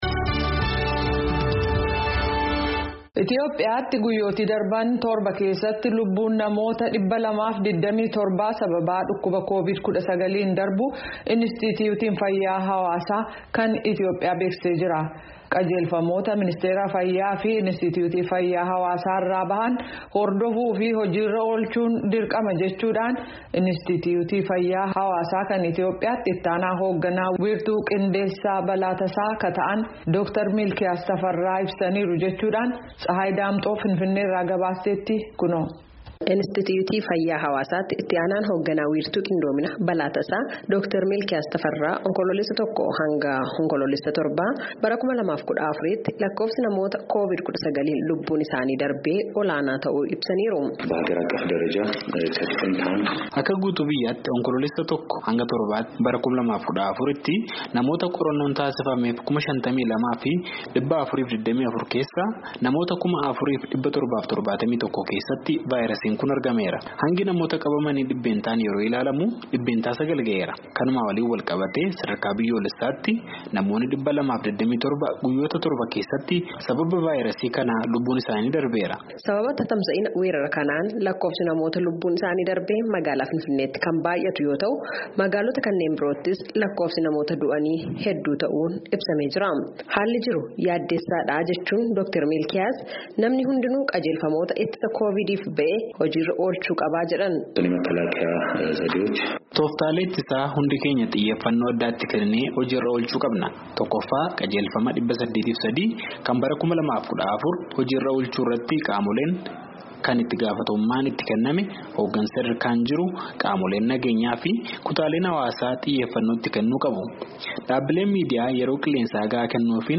Gabaasa